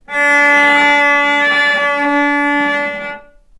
vc_sp-C#4-ff.AIF